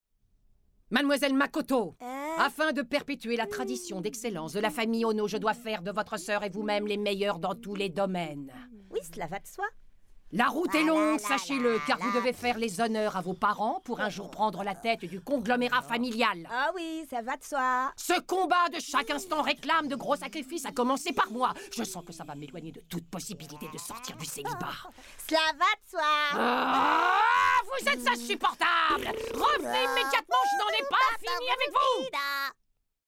Voix de doublage